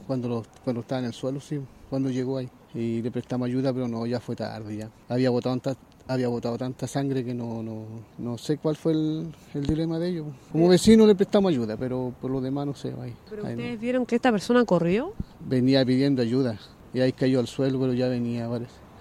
cu-testigo.mp3